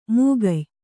♪ mūgay